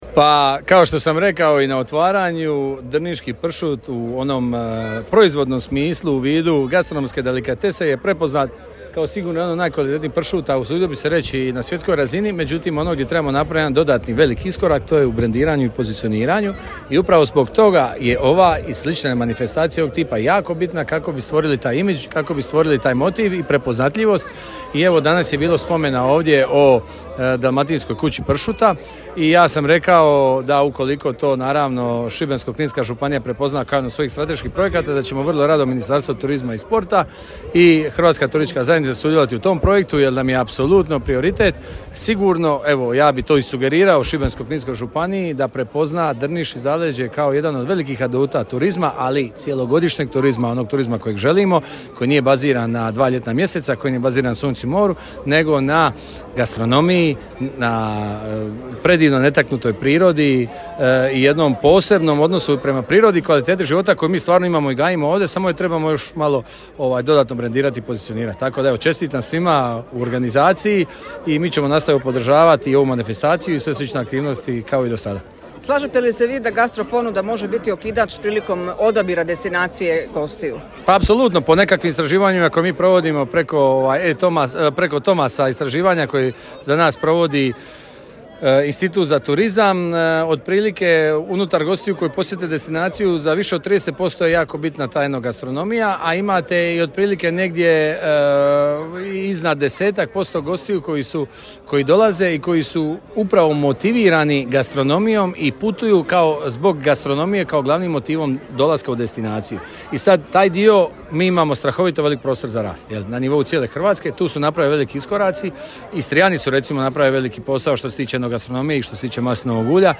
Večeras je u Drnišu svečano otvoren 8. Međunarodni festival pršuta, u organizaciji Turističke zajednice Grada Drniša te Grada Drniša.
Evo izjava:
Ministar Tonći Glavina: